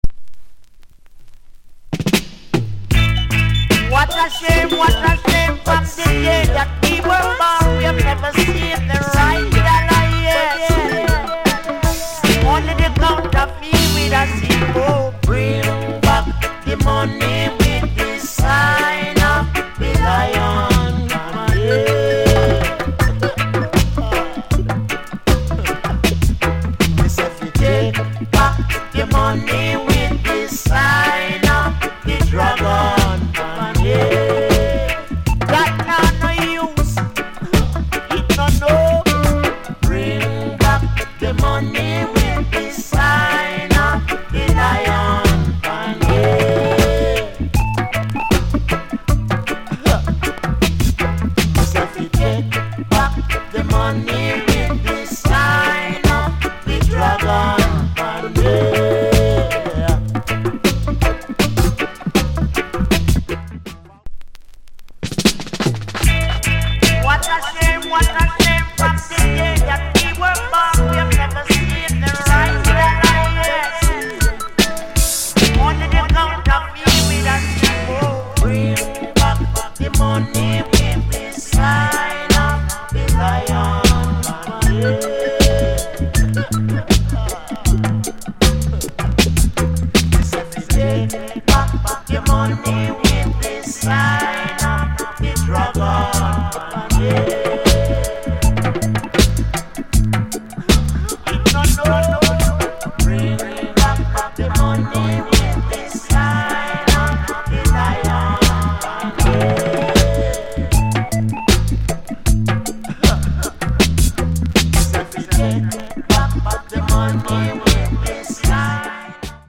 Roots Rock
Male Vocal